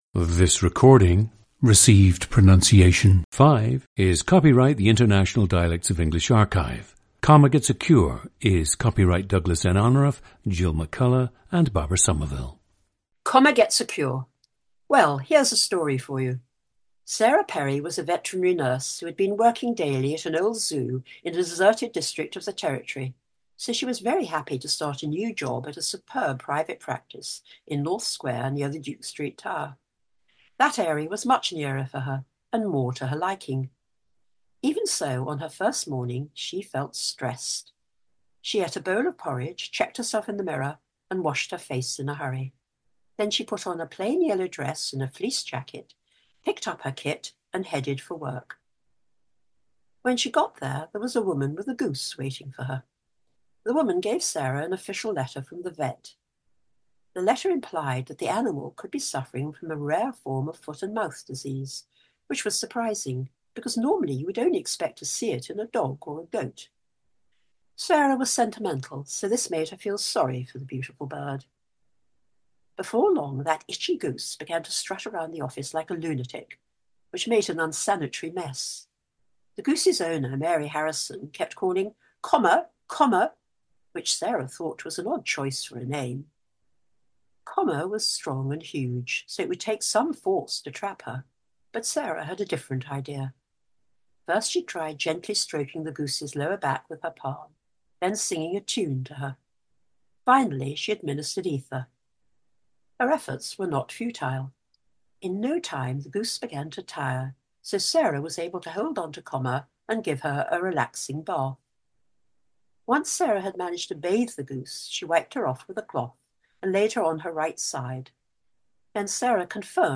Received Pronunciation 5